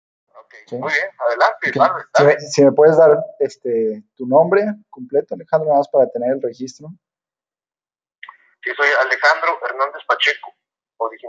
Audio de entrevista